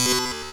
retro_fail_sound_05.wav